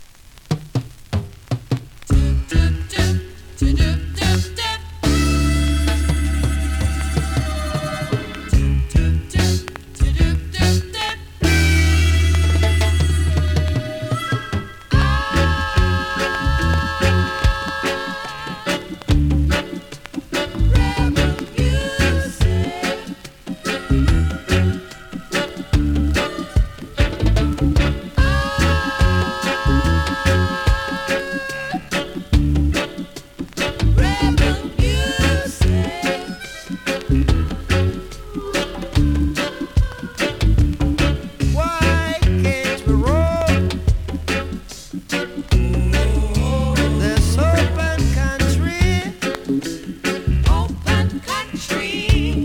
※うすくノイズ
スリキズ、ノイズ比較的少なめで